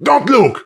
panelopen02.ogg